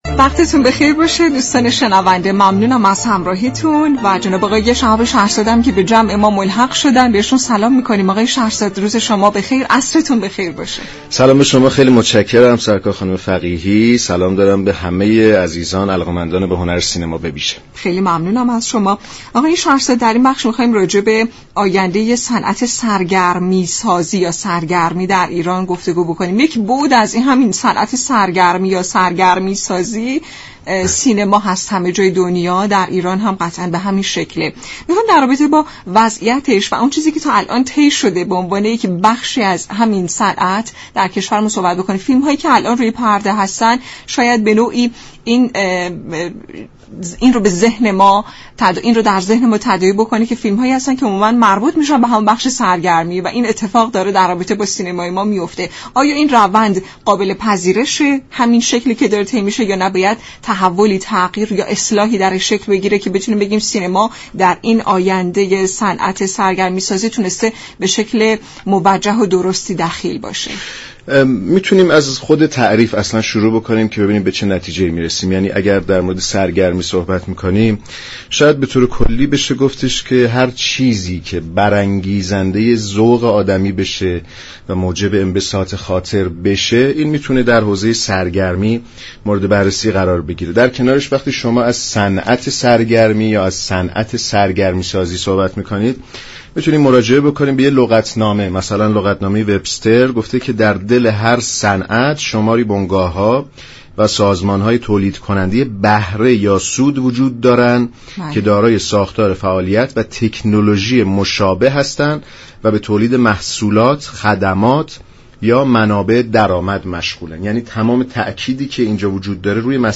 فیلمساز و منتقد سینما در گفت و گو با برنامه «حوض نقره» رادیو ایران